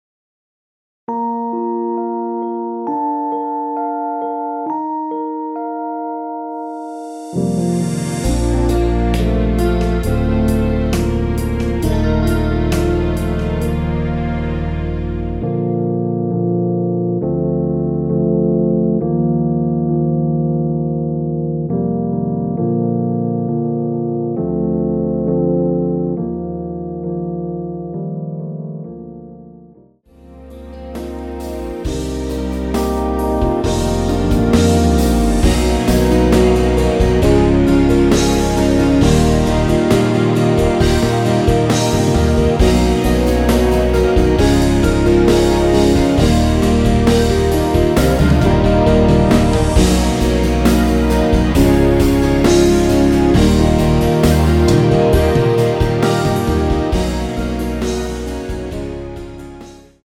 원키에서(-4)내린 MR입니다.
Bb
앞부분30초, 뒷부분30초씩 편집해서 올려 드리고 있습니다.
중간에 음이 끈어지고 다시 나오는 이유는